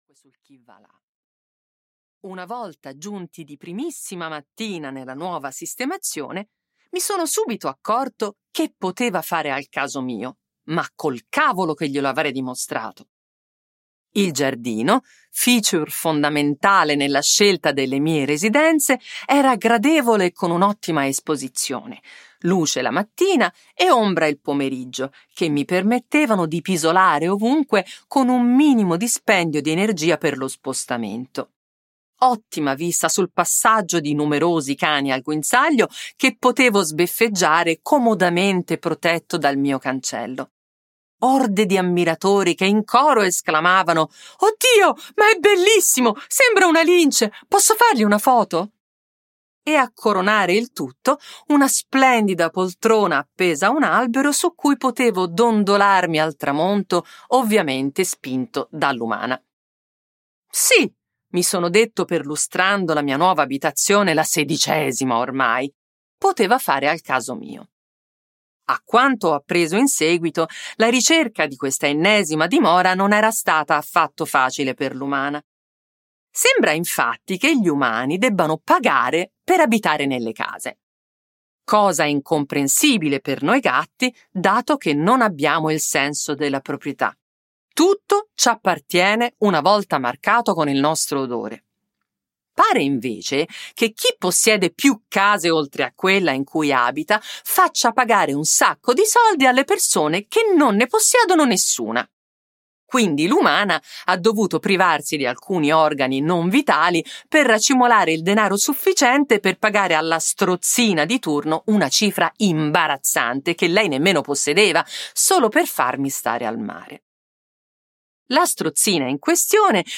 "Natale con Sir Thomas" di Federica Bosco - Audiolibro digitale - AUDIOLIBRI LIQUIDI - Il Libraio
• Letto da: Federica Bosco